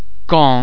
en em an am vowel-base similar to ong in (pong)
·[aen]
en_caen.mp3